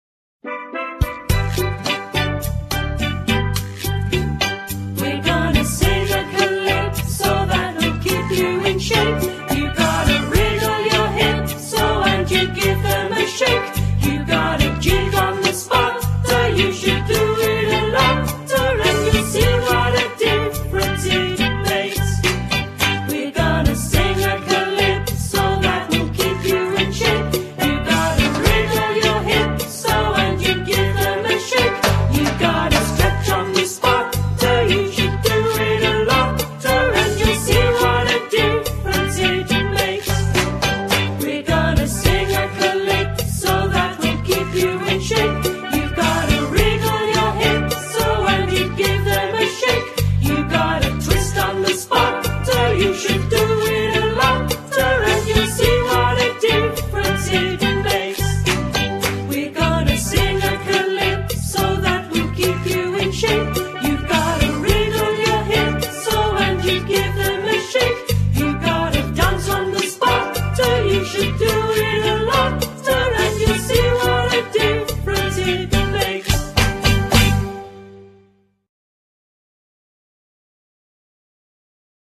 Here is an MP3 that I found on the internet that demonstrates how it should be sung and the right words/lyrics.